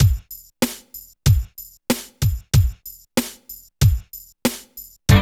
29 DRUM LP.wav